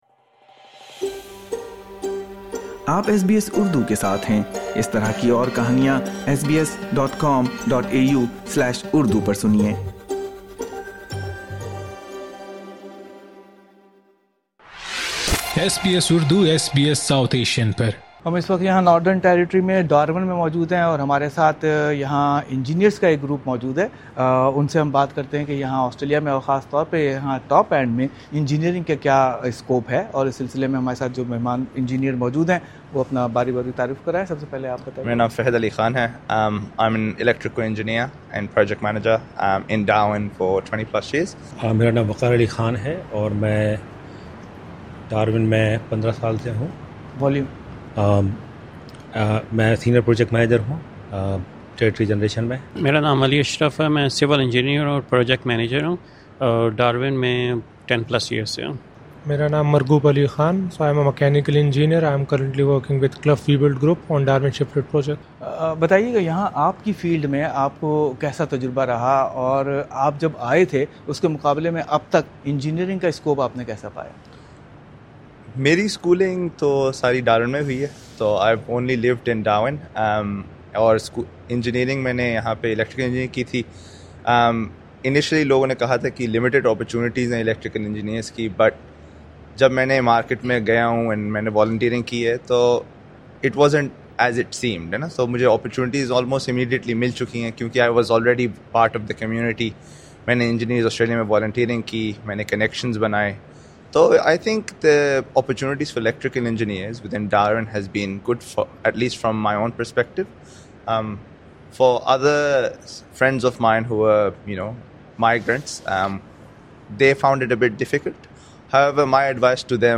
The engineering sector in Australia is currently going through a number of challenges, however there are still employment opportunities for skilled engineers in a variety of industries, especially in regional areas where development projects are progressing rapidly. A panel discussion with engineers working at Darwin highlighted the issues facing the sector, the need for skills and future possibilities.